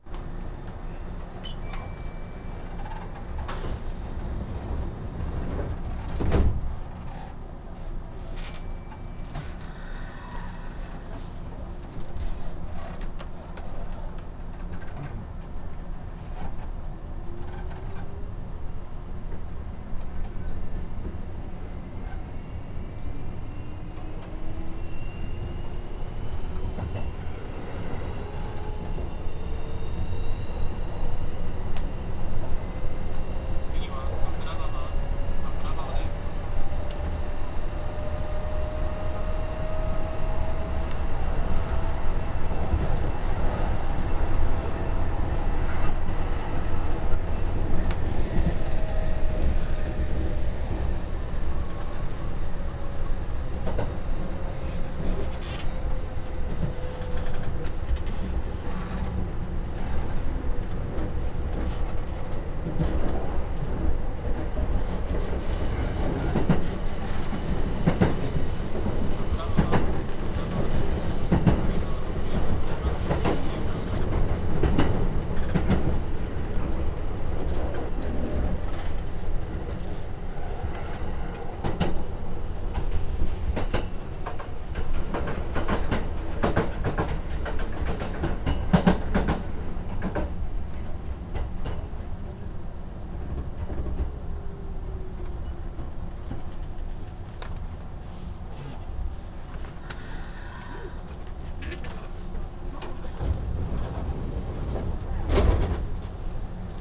・2000系走行音
【名古屋線】霞ヶ浦→阿倉川（1分46秒：837KB）
ごく普通の抵抗制御車の音となります。